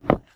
STEPS Wood, Dense, Walk 31.wav